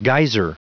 Prononciation du mot geyser en anglais (fichier audio)
Prononciation du mot : geyser